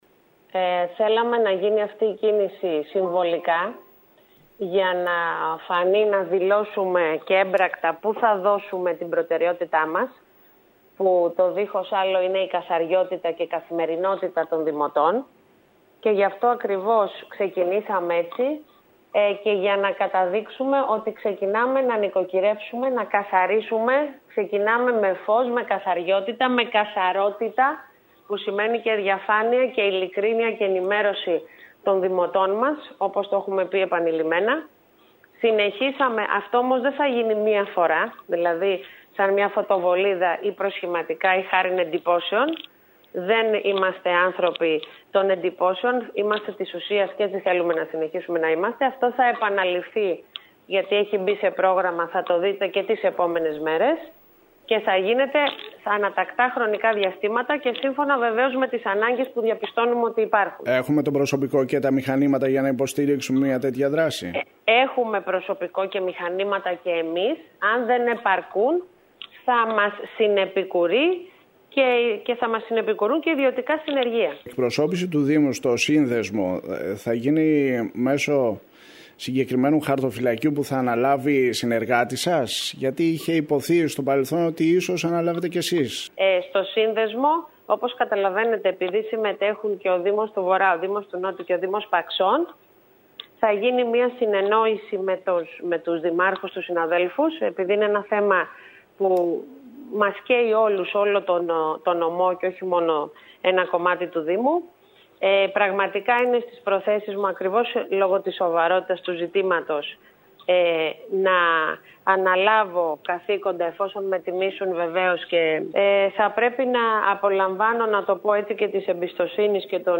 Μιλώντας στην ΕΡΤ Κέρκυρας, η Δήμαρχος Μερόπη Υδραίου, αναφέρθηκε εκτενώς στα θέματα καθαριότητας, με αφορμή το πλύσιμο δρόμων, πεζοδρομείων και πλατειών καθώς και την αντικατάσταση κάδων.